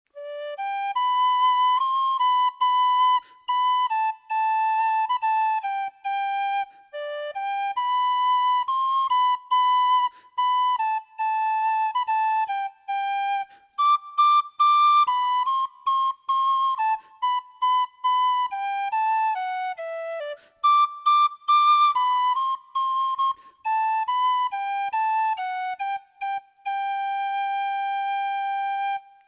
Traditional Irish Music - learning resources
Traditional Irish Music -- Learning Resources I'll Tell Me Ma (Belfast Street Song) /